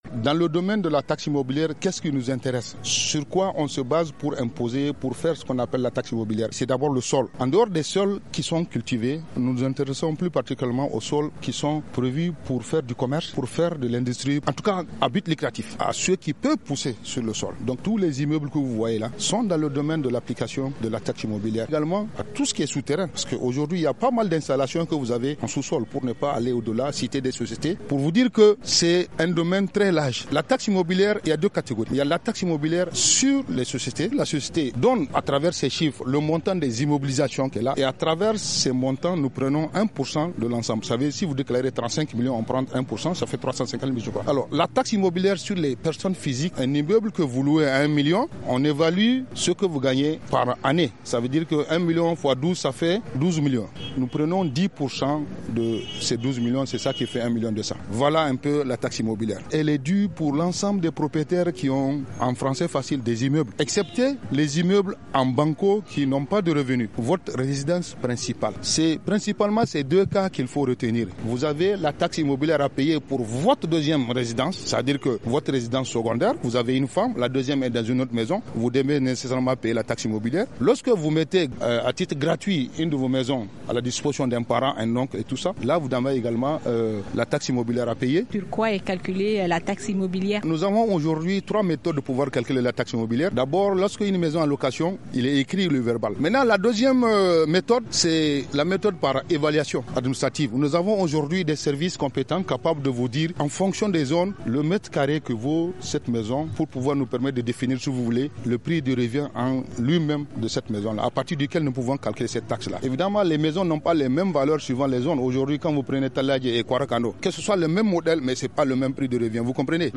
Dans le cadre de la Journée Mondiale de l’Information sur le Développement célébrée le 24 Octobre, un salon National de l’Information sur le Développement au Niger a eu lieu à la Place Toumo de Niamey.